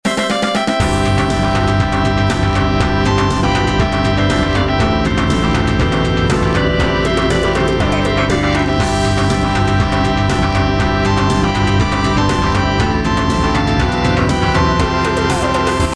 試聴用 MP3ファイル ループ再生になっておりますので、BGMなどの参考にしてください。
BGM 明るい 普通